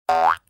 joins.ogg